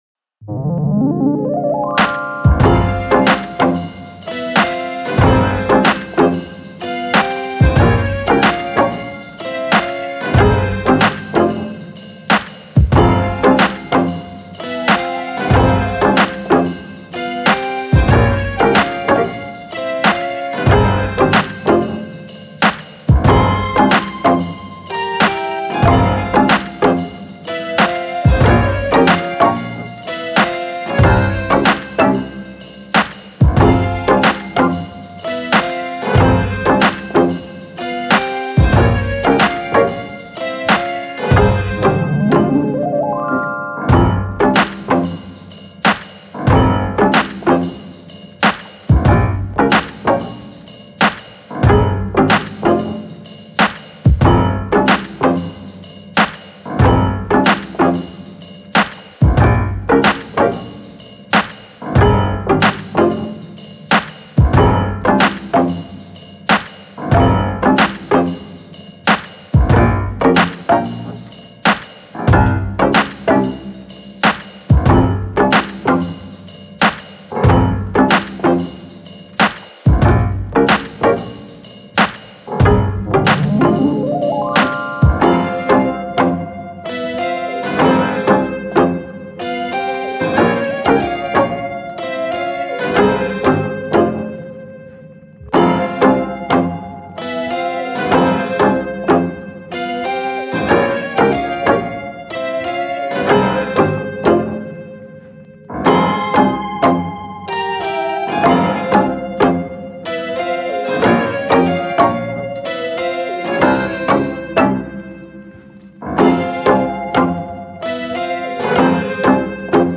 Quirky circus flavoured hip hop delights with eastern feel.